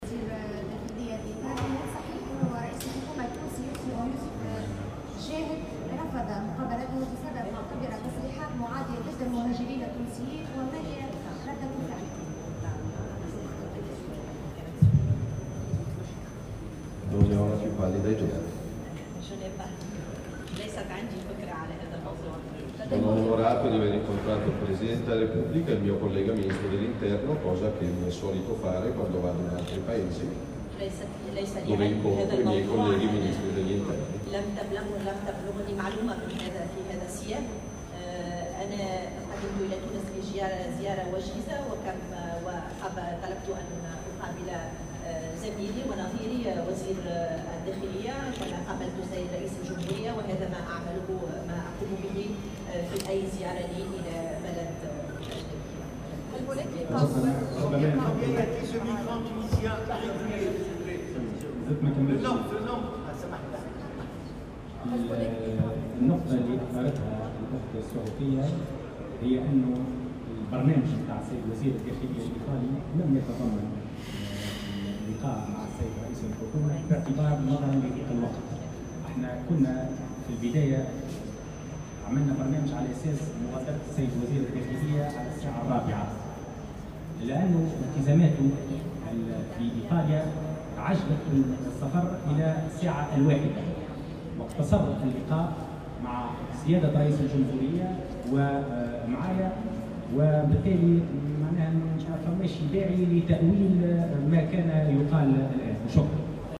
نفى وزير الداخلية هشام الفراتي اليوم الخميس ما راج من أخبار بشأن رفض رئيس الحكومة يوسف الشاهد مقابلة نائب رئيس الوزراء ووزير الدّاخليّة الإيطالي، ماتيو سالفيني، وذلك في رده على سؤال بشأن هذا الأمر في ندوة صحفية انعقدت ظهر اليوم بمقر وزارة الداخلية.